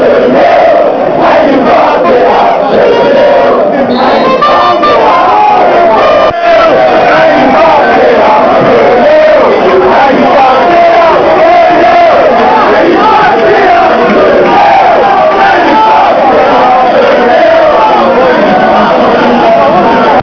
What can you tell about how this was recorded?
Chants from the match against Orient Quality is pretty rough but if you were there they will probably put a smile on your face, if you weren't you will probably wish you were!